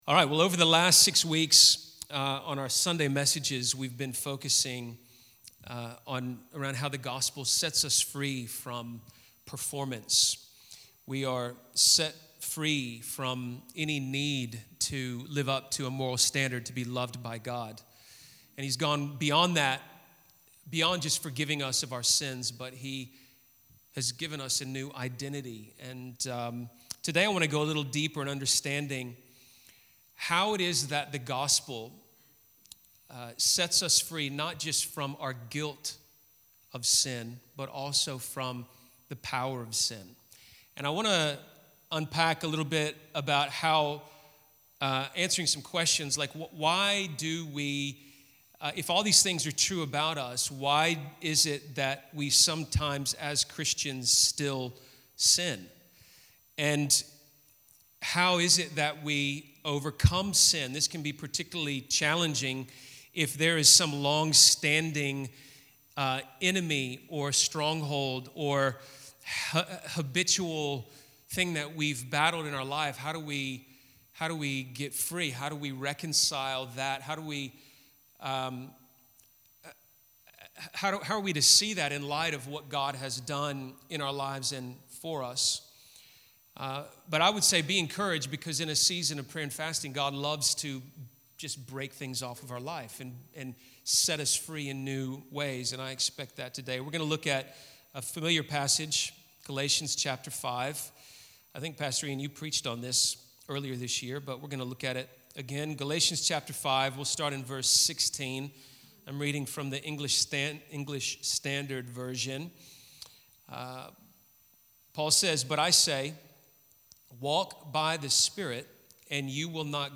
In this message, we look at the relationship between our new nature in Christ and our ongoing battle against the passions and desires of the flesh.